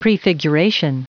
Prononciation du mot prefiguration en anglais (fichier audio)
Prononciation du mot : prefiguration